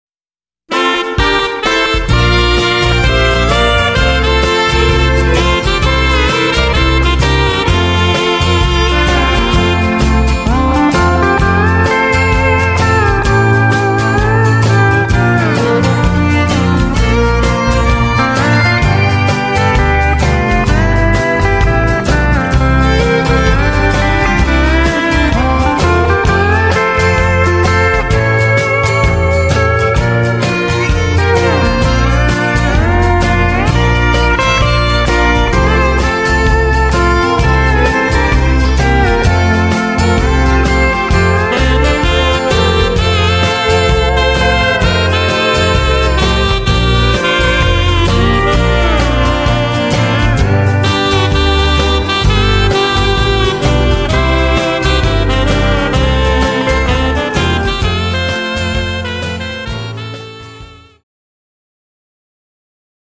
Cued Sample
Two Step